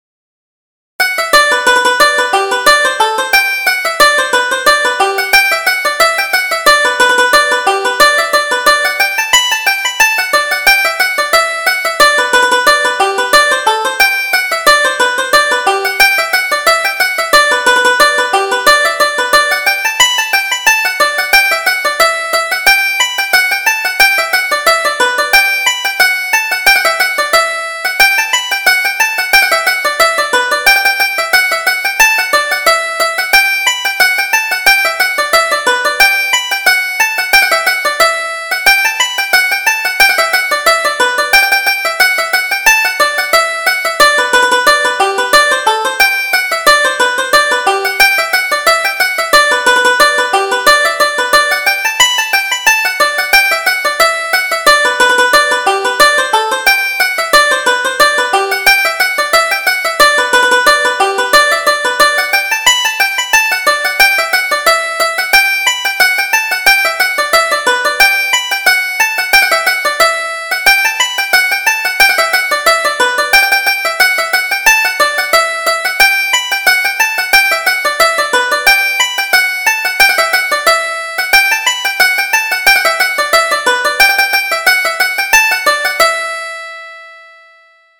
Reel: Pay the Girl Her Fourpence